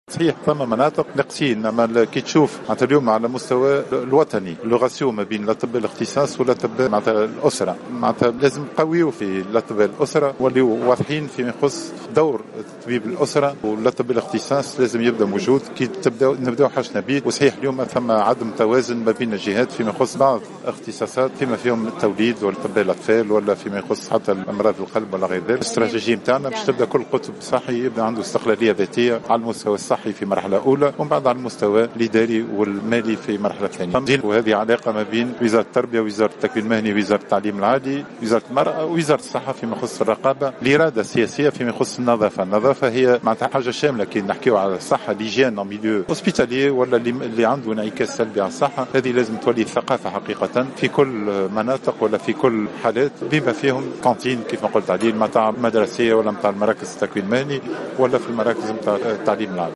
في تصريح